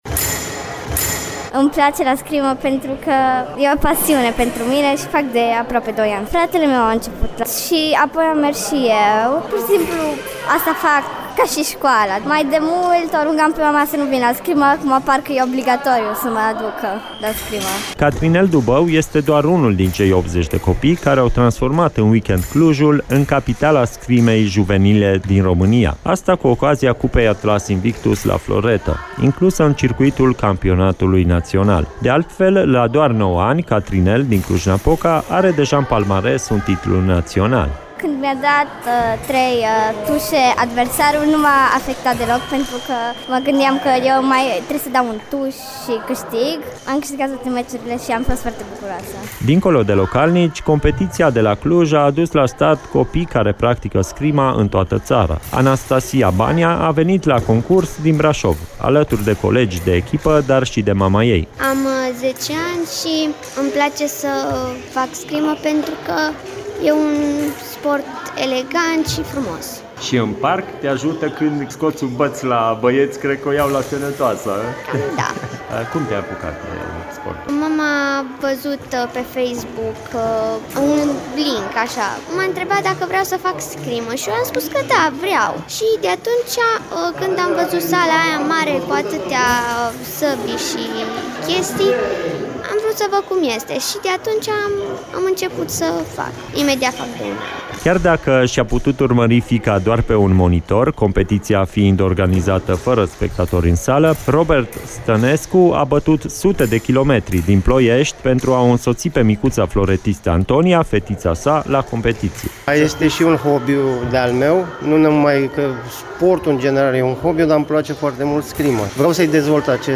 EBS Radio a fost la fața locului, în a doua și totodată ultima zi a concursului şi a discutat cu copii, părinți, antrenori și organizatori.